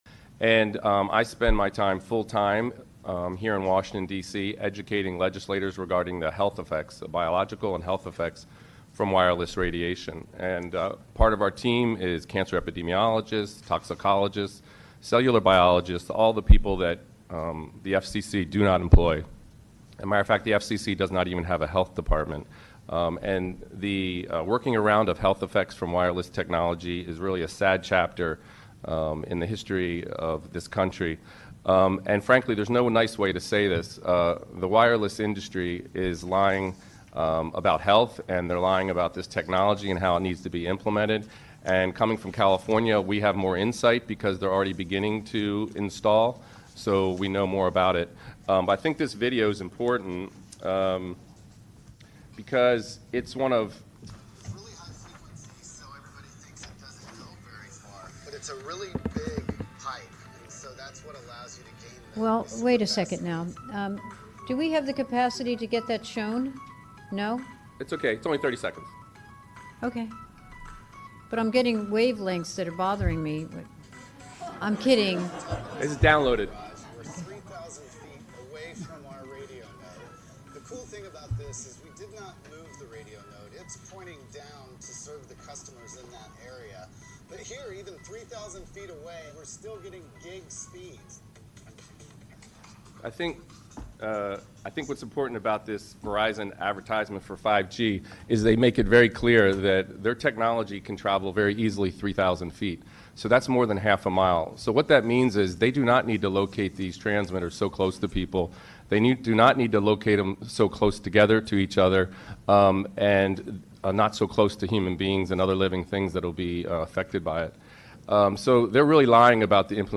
Scientists Warn of Health Effects: Washington DC Council 5G Small Cell Roundtable